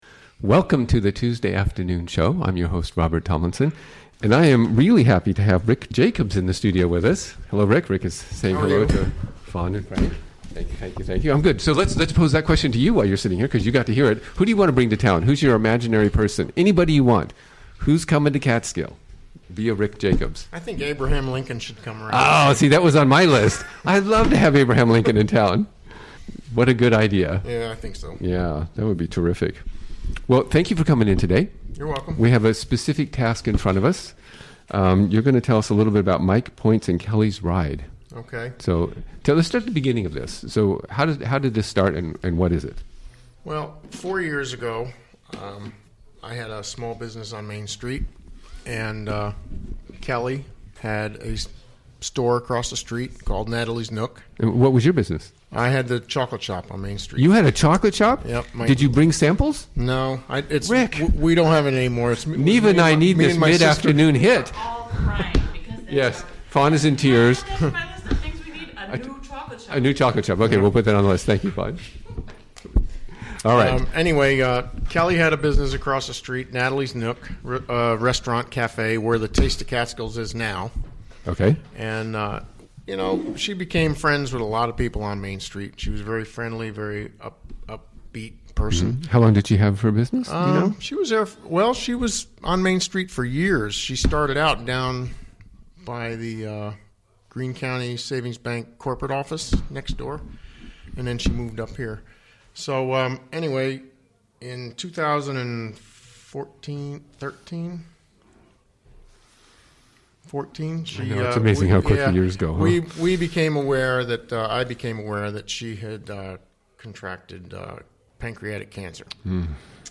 Recorded live during the WGXC Afternoon Show of Tuesday, May 9, 2017.